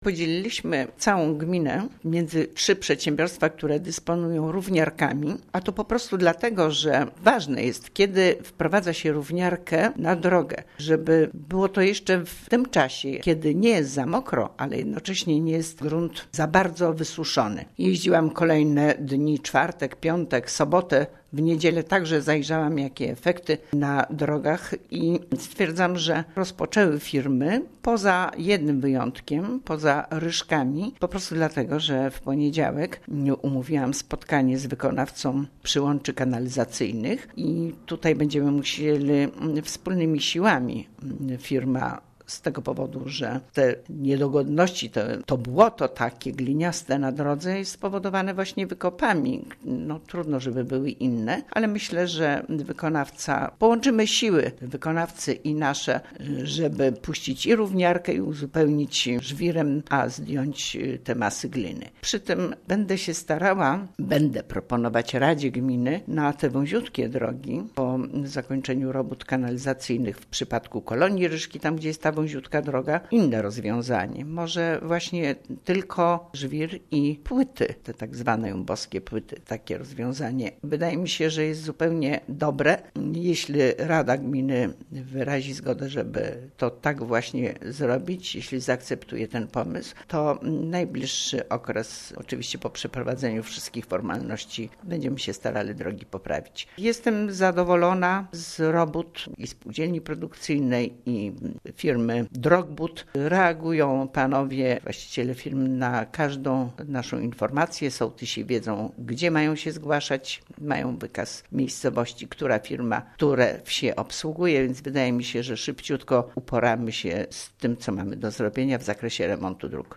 W�jt Gminy �uk�w Kazimiera Go�awska